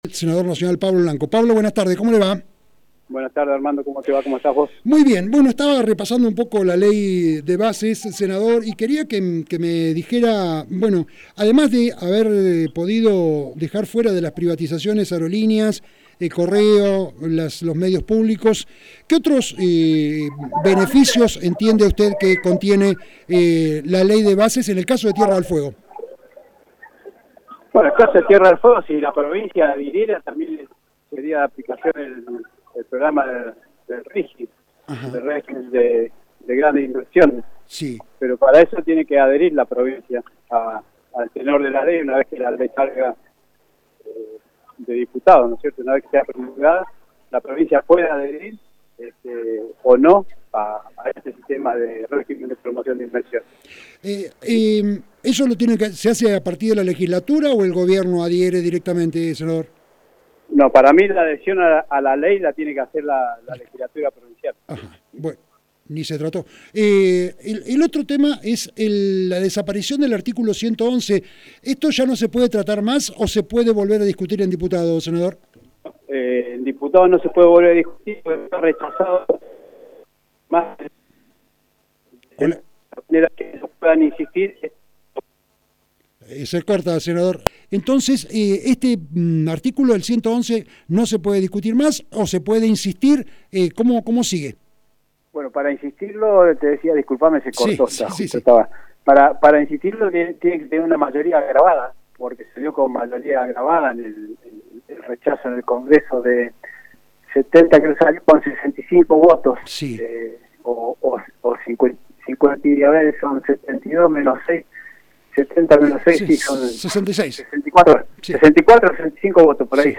Rio Grande 17/06/2024.- El senador pablo Blanco, en diálogo con este medio, señaló que Tierra del Fuego no es una de las más beneficiadas por la ley de bases, respecto a los trabajadores solo se logró que no haya contratos reiterados, las tarifas seguirán llegando sin subsidios, en coparticipación solo se recibirá un plus por impuesto a los bienes personales y solicitó la adhesión de la provincia al regimen de Grandes Inversiones, RIGI, que debe concretar la legislatura.